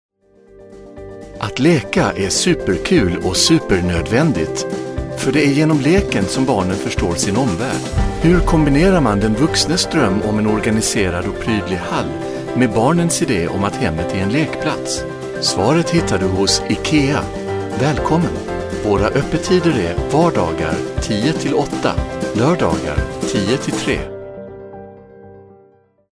Swedish male voice overs
Swedish male voice over